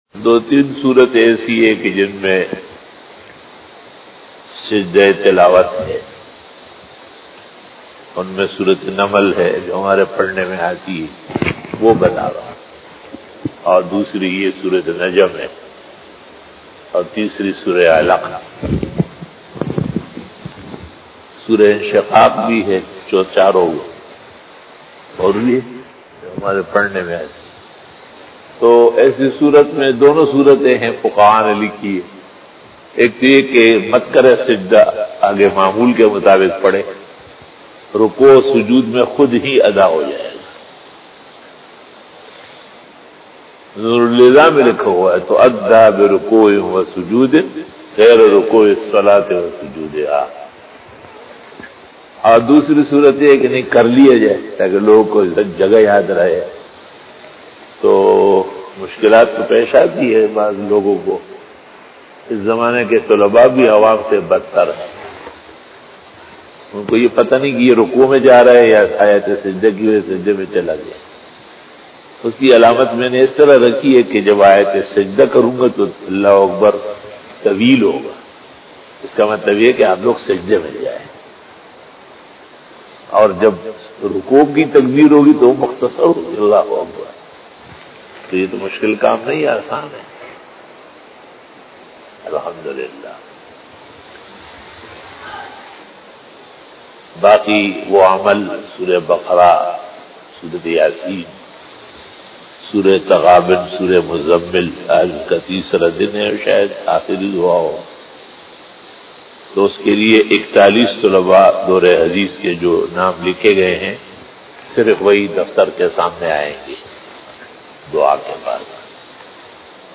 Fajar bayan
بیان بعد نماز فجر